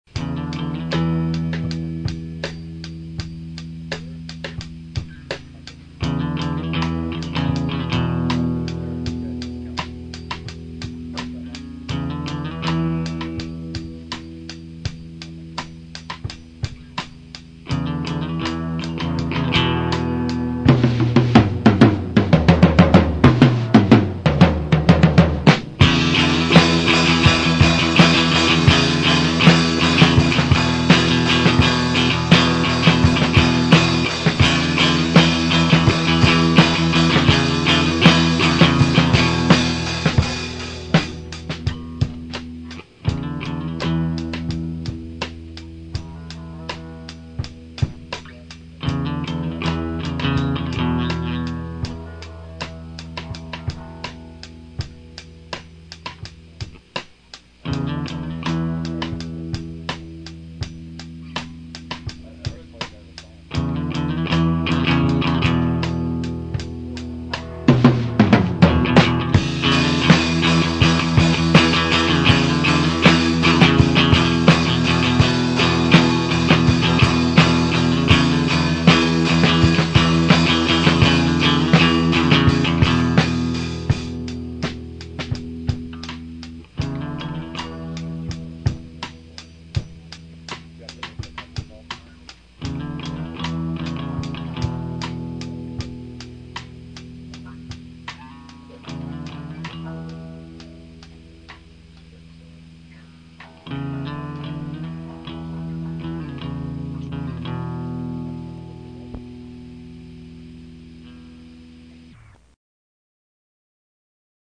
Classic 1990s Lehigh Valley punk
punk rock See all items with this value
cassette